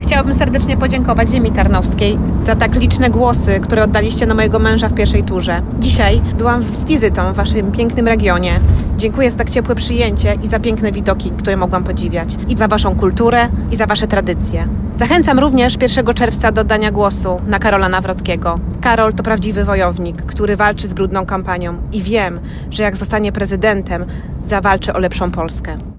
W rozmowie z radiem RDN, podziękowała mieszkańcom za okazane zaufanie w pierwszej turze wyborów prezydenckich i zaapelowała o udział w drugiej turze wyborów.